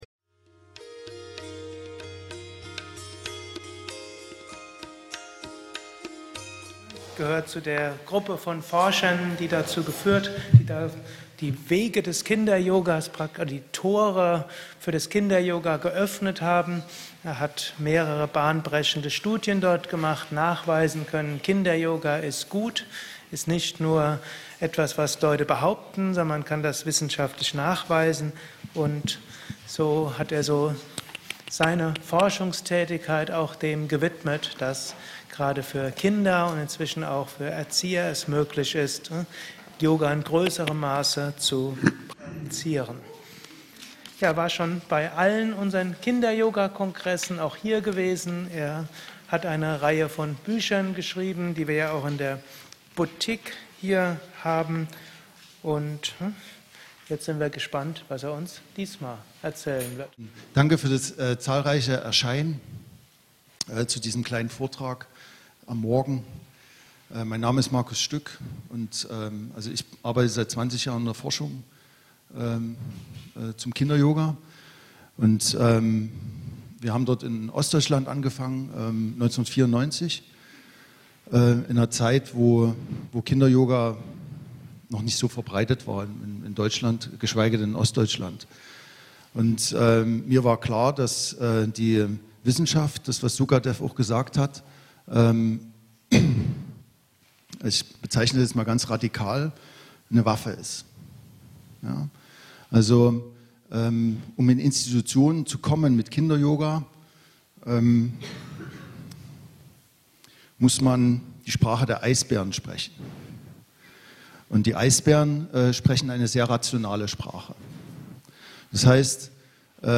Aufnahme vom Kinderyoga Kongress am 8. Juni 2013 bei Yoga Vidya in Bad Meinberg.